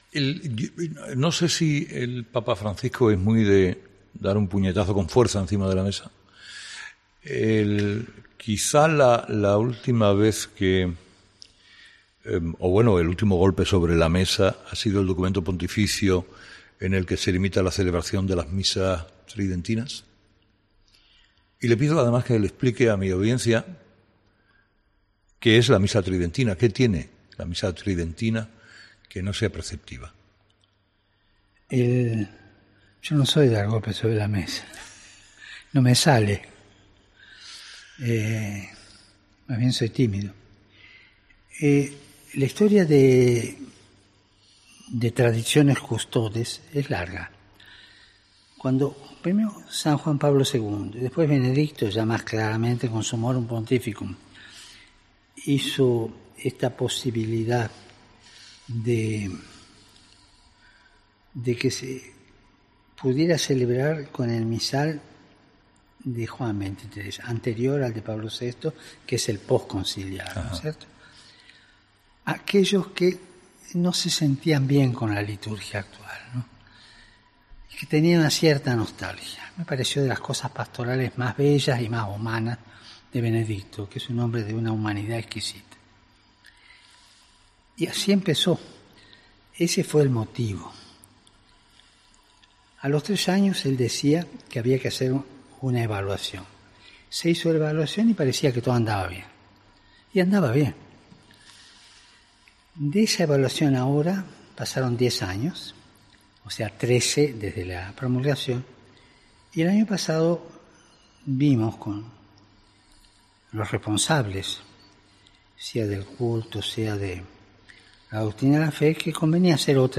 Francisco explicaba a Herrera que tres años después de que Benedicto XVI ofreciera la posibilidad a aquellos que no se sentían bien con la liturgia actual de celebrar la misa con el rito anterior a Pablo VI, se hizo una evaluación que resultó ser satisfactoria.